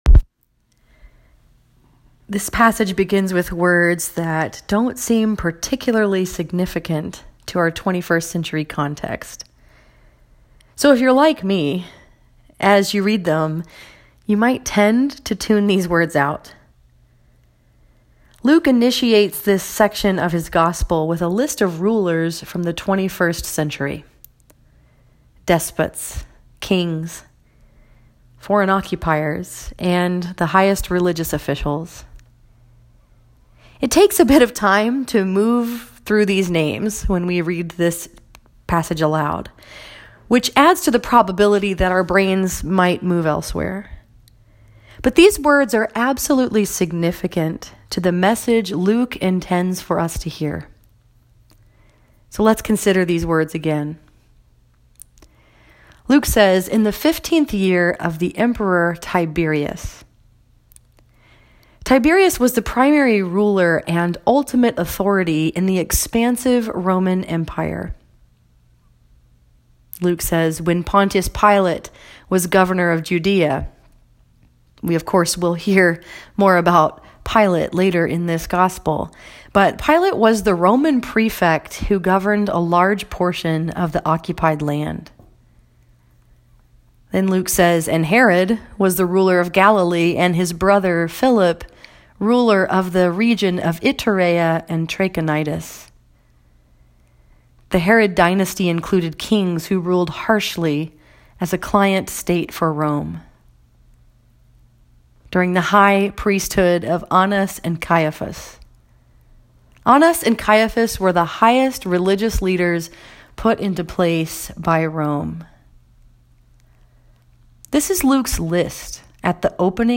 This sermon was preached at White Lake Presbyterian Church in White Lake, Michigan and was focused upon Luke 3:1-6.